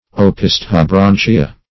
Opisthobranchia \O*pis`tho*bran"chi*a\, Opisthobranchiata